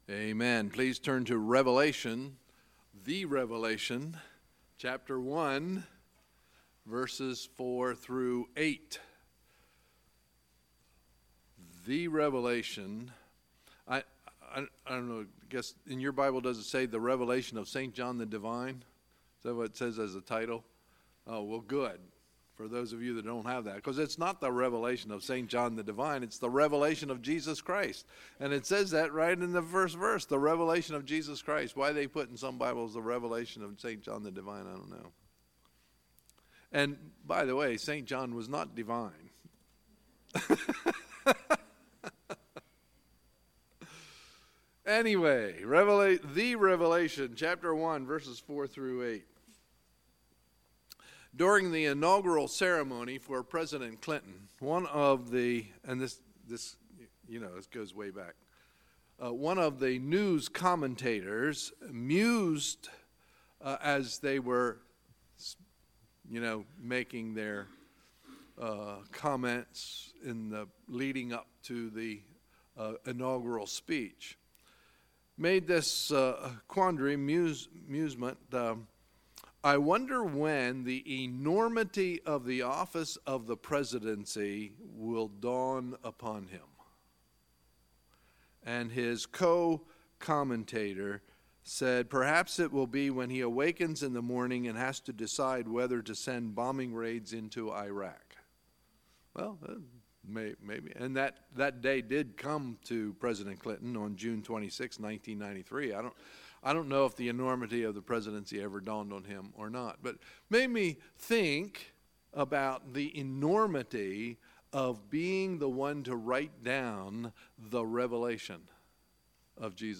Sunday, January 14, 2018 – Sunday Evening Service